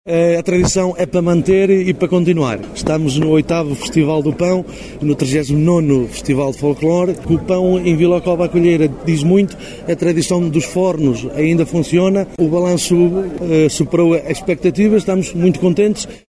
Fernando Guedes, Presidente da Junta de Freguesia de Vila Cova à Coelheira, em declarações à Alive FM, disse que a tradição é para manter e para continuar, “o balanço superou as espectativas, estamos muito contentes…“.